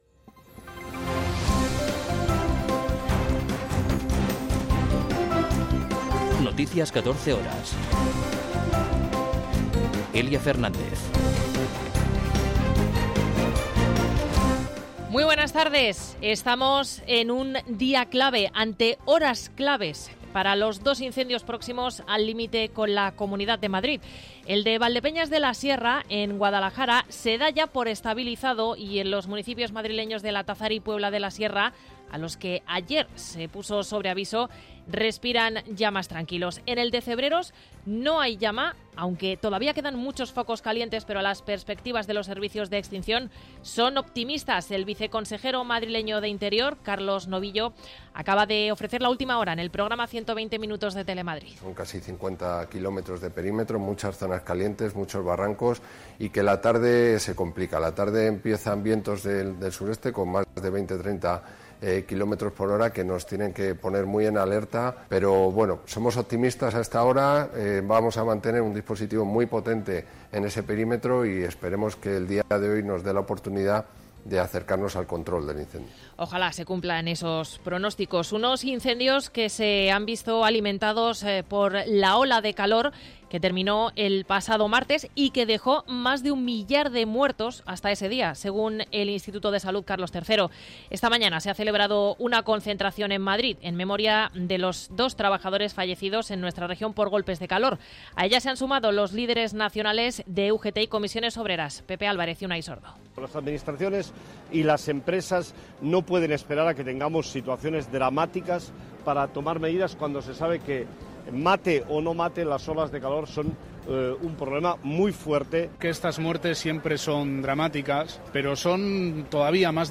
Noticias 14 horas 21.07.2022